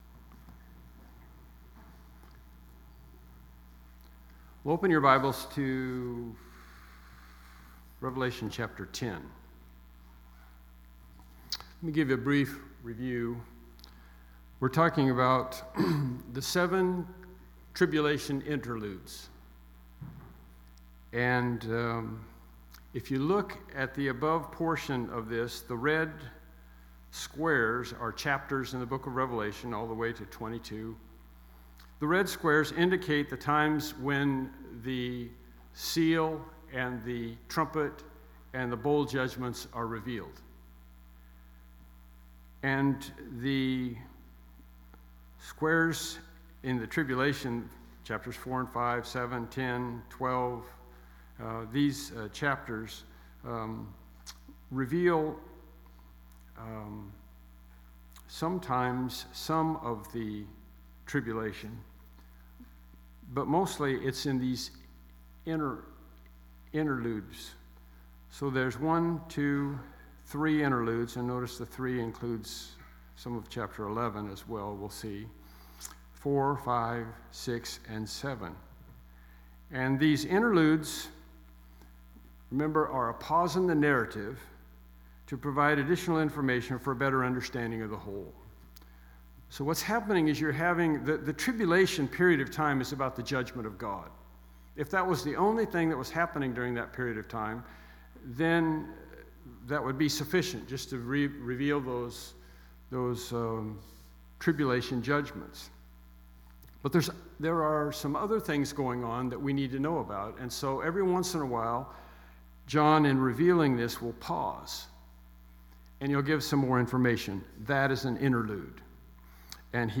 Evening Sermons Service Type: Evening Worship Service « The Jerusalem Council